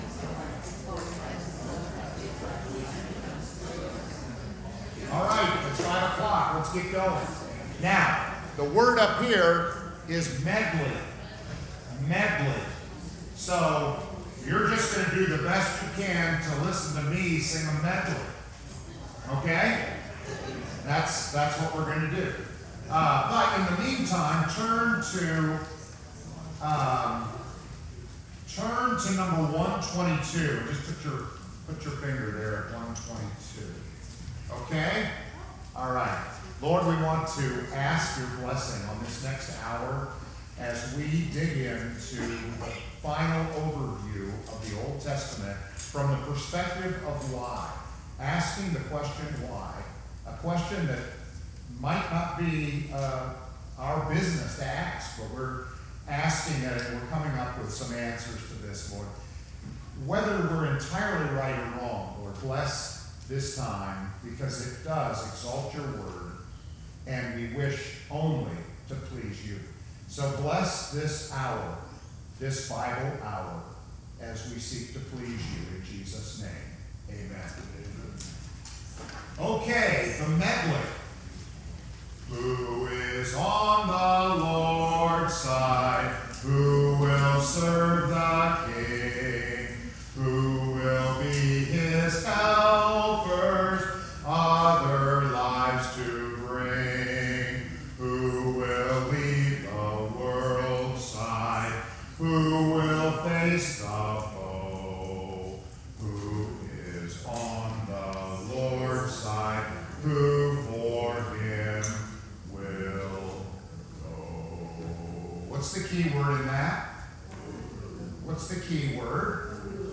This is the final lesson on the Old Testament.